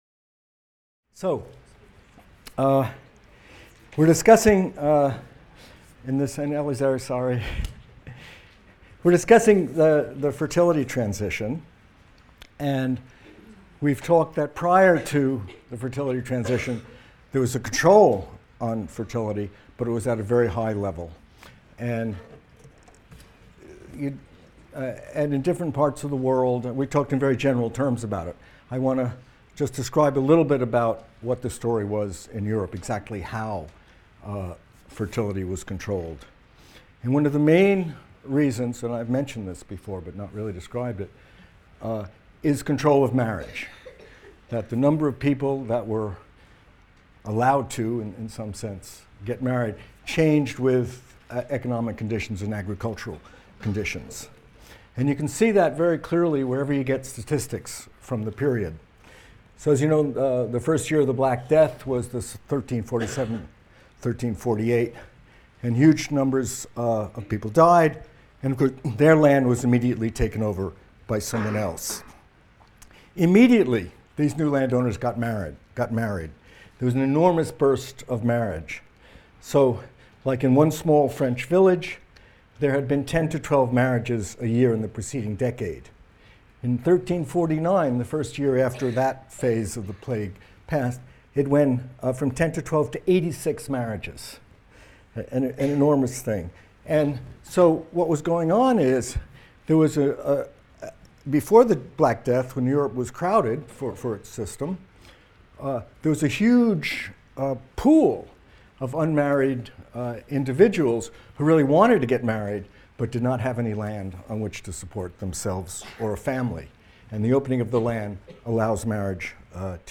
MCDB 150 - Lecture 9 - Demographic Transition in Europe | Open Yale Courses